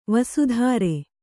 ♪ vasu dhāre